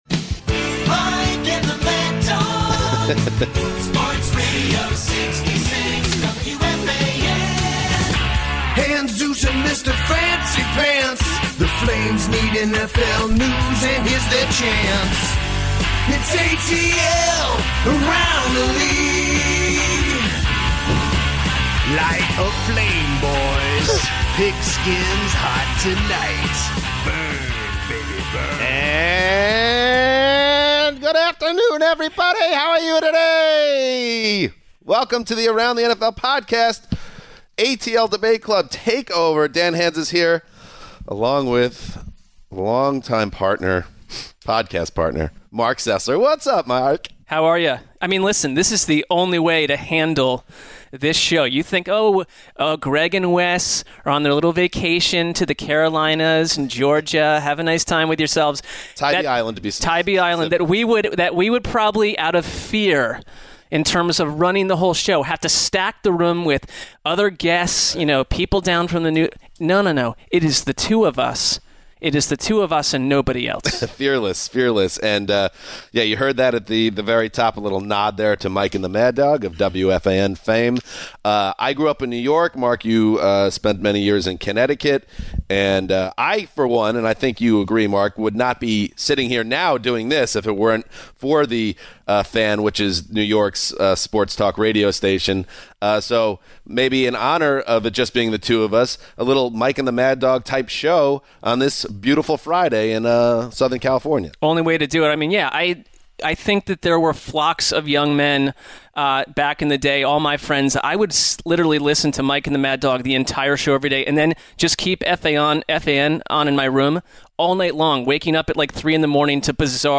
Browns On Hard Knocks & Listener Call-Ins